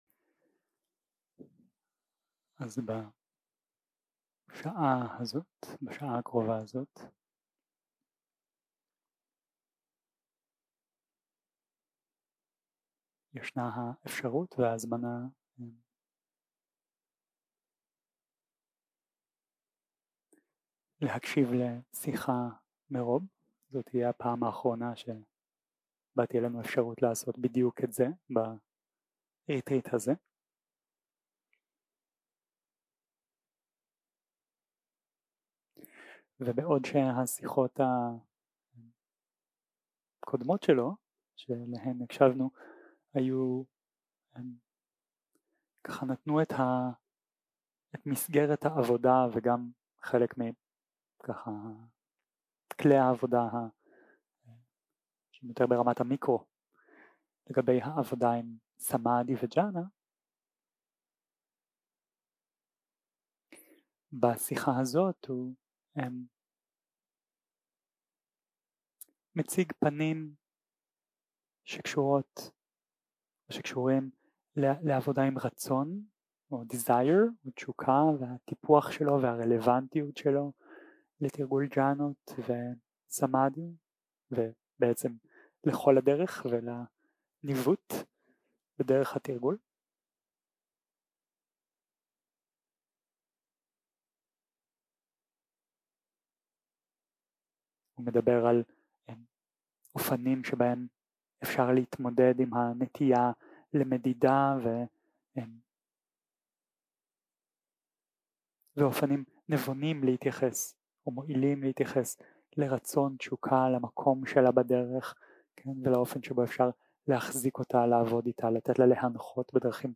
סוג ההקלטה: שיחות דהרמה
ריטריט סמאדהי